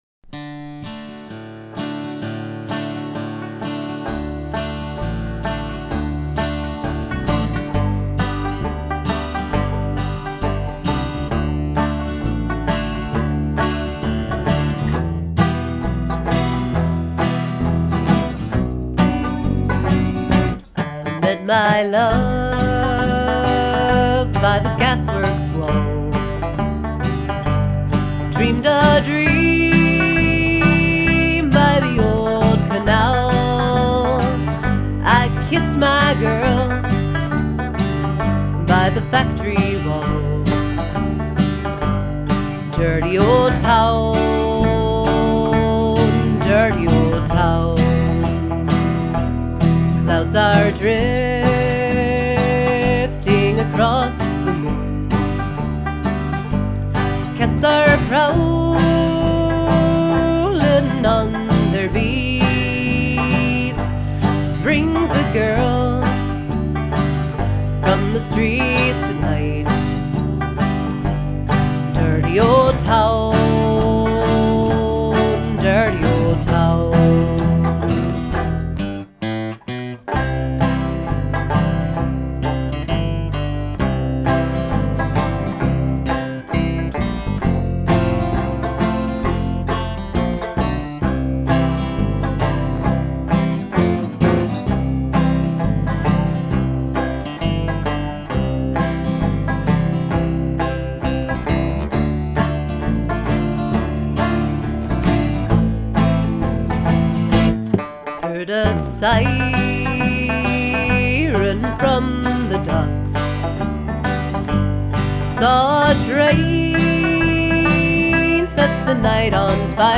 Cleveland's Irish Acoustic Rock